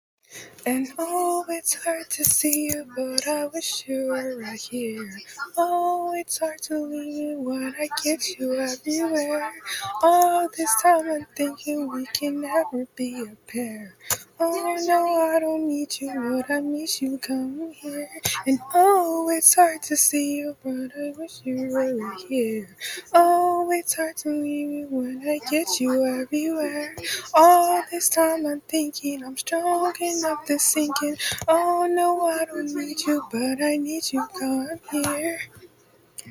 the mic quality isn't the best